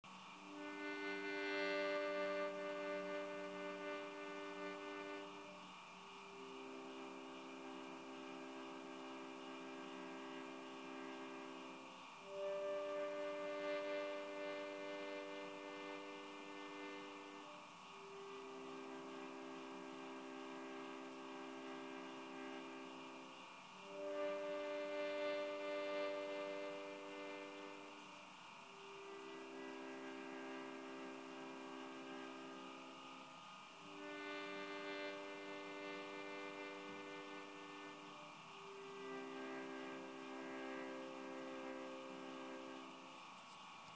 Играть на минимальной громкости.
Maksimalno-tiho.mp3